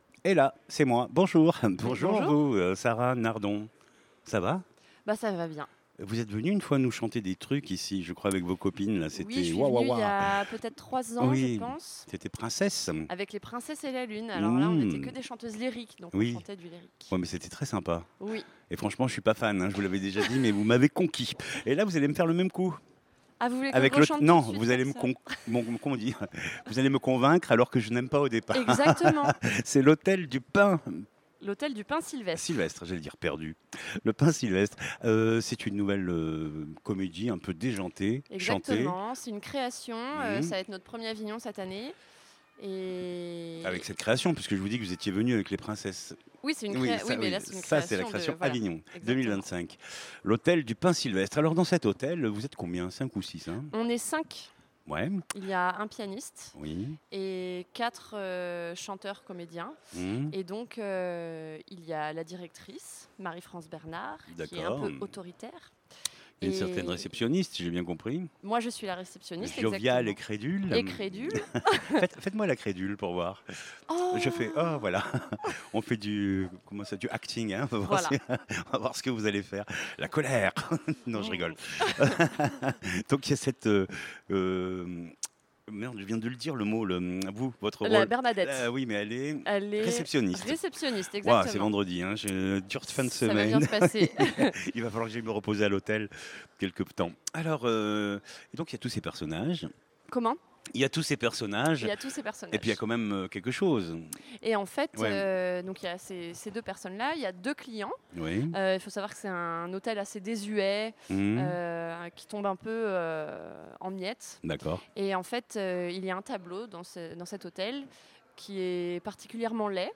auteure et interprete et chanteuse